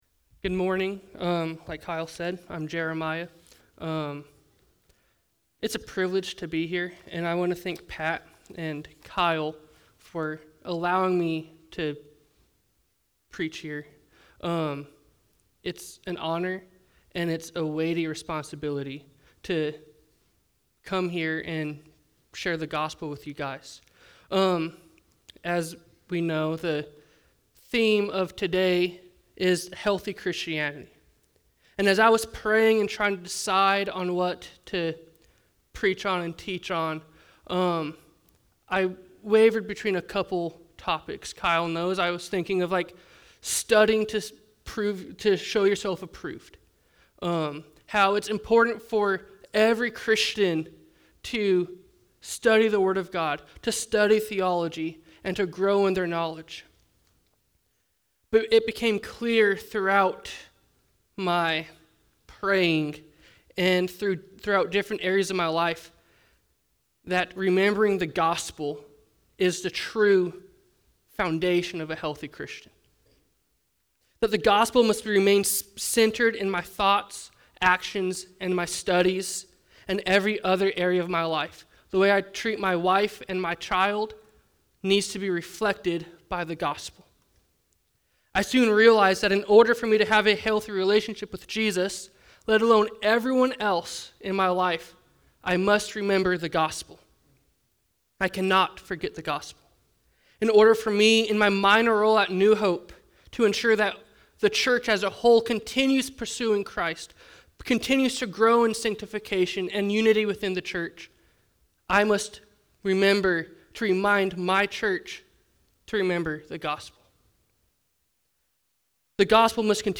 Fall Bible Conference